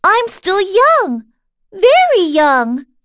Dosarta-vox-dia_03.wav